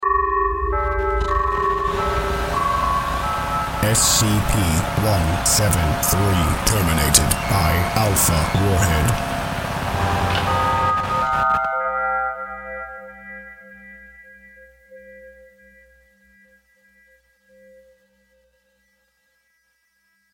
SCp death by warhead